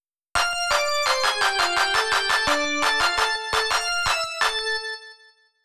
メロディと同じリズムでクラップ（手拍子）の音を重ねてみます。